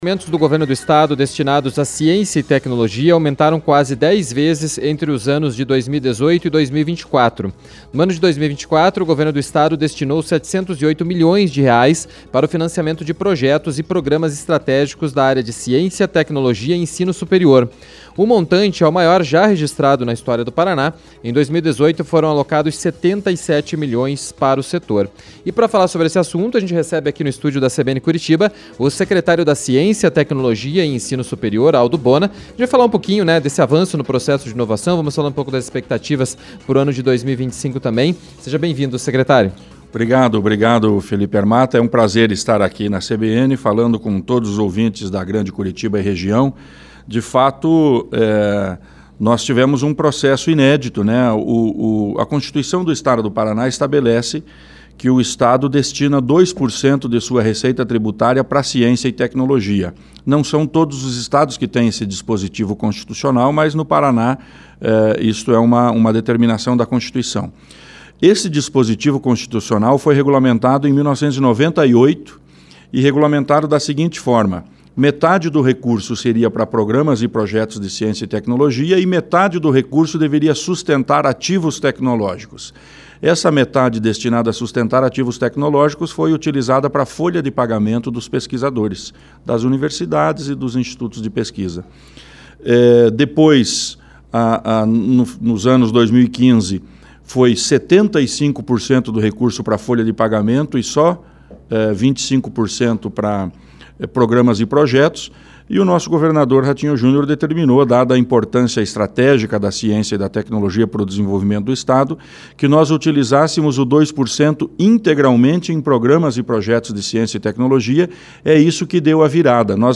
conversou com o Secretário da Ciência, Tecnologia e Ensino Superior, Aldo Bona, para falar desse momento de avanço no processo de inovação em todas as regiões do Estado, e o impacto para a população do Paraná, além dos investimentos previstos para este ano de 2025.